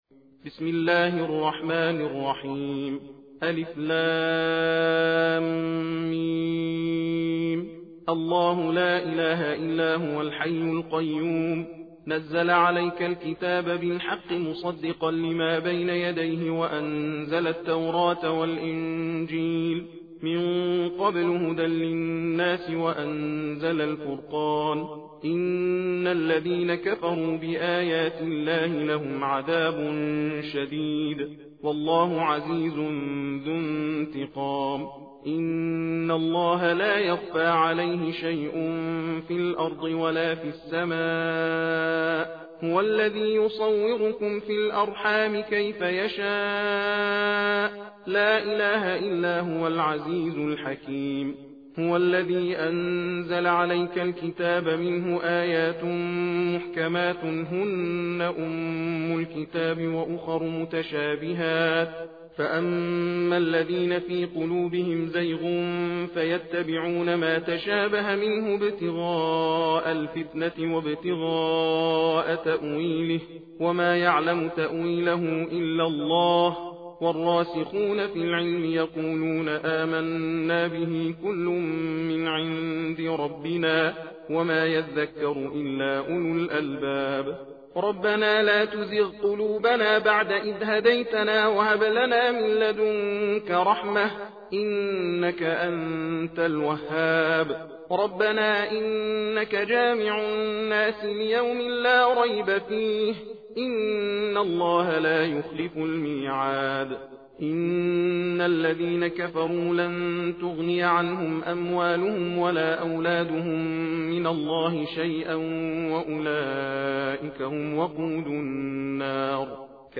تحدیر و تندخوانی سوره آل عمران